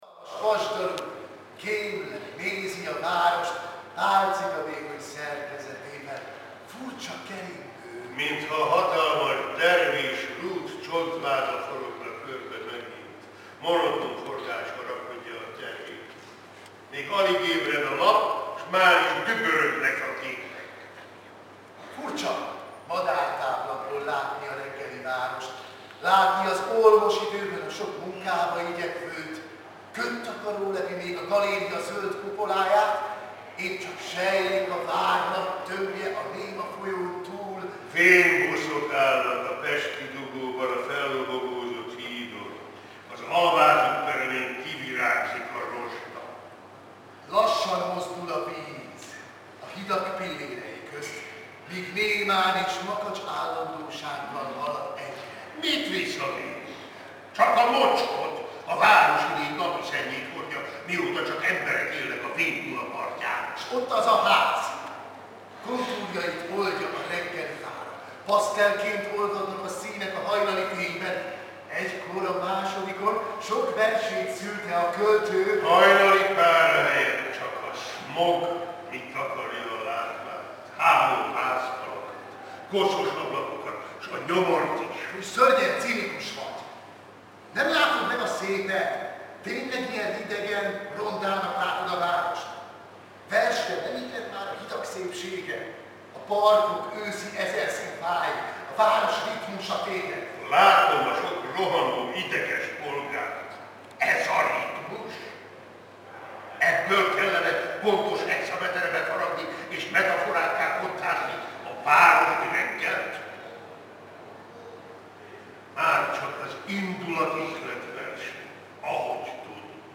Helyzetjelentés Aquincumból
Audiórészlet az elhangzott versből (mp3)